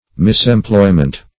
Misemployment \Mis`em*ploy"ment\, n. Wrong or mistaken employment.